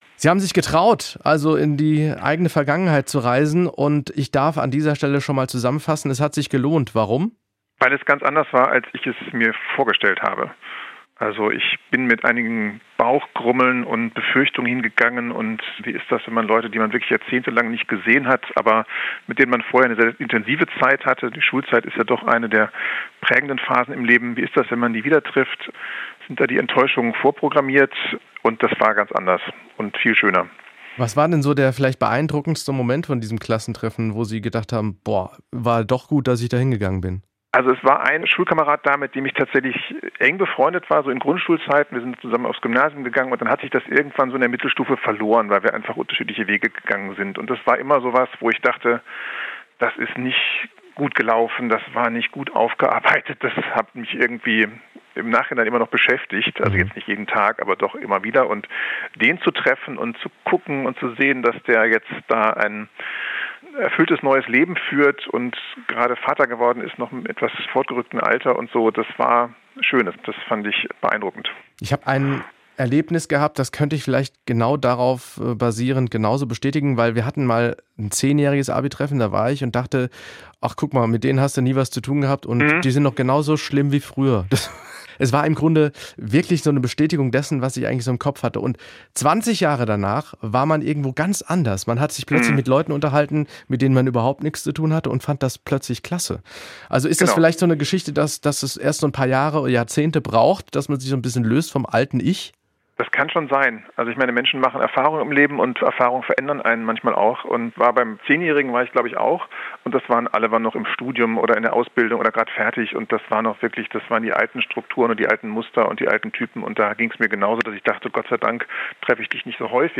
Erfahrungsbericht: So überraschend kann ein Klassentreffen sein